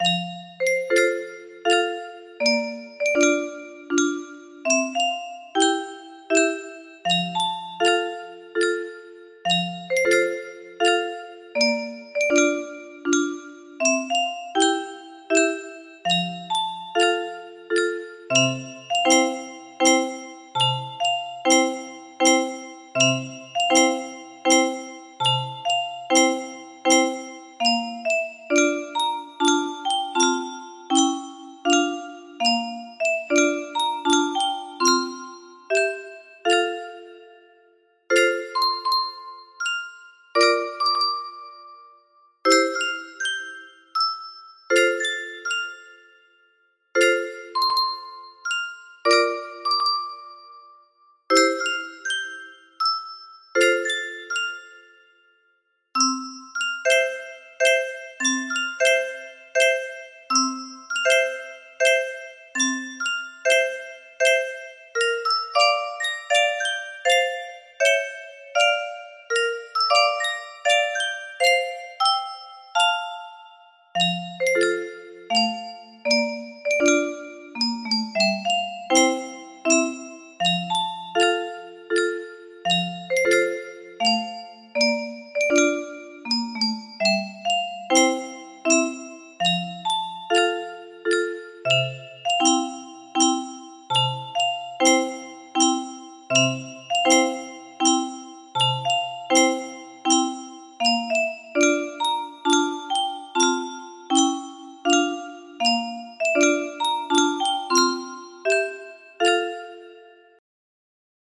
Gaudeamus Igitur music box melody
Full range 60